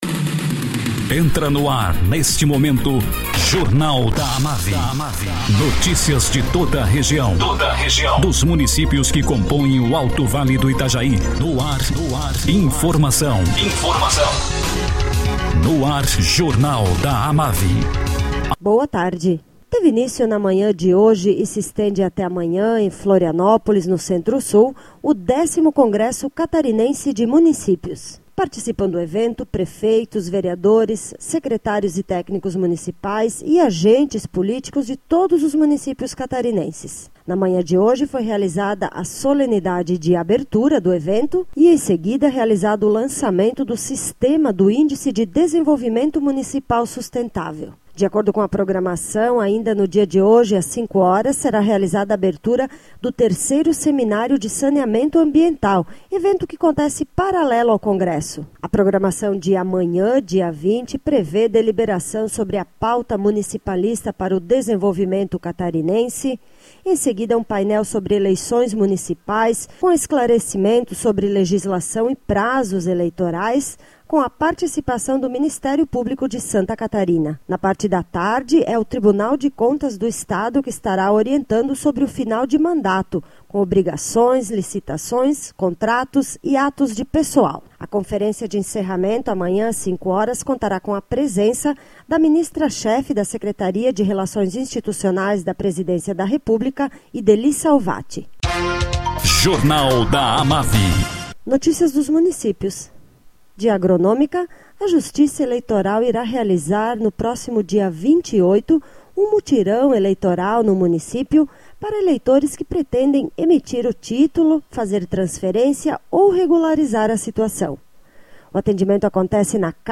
Acervo de boletins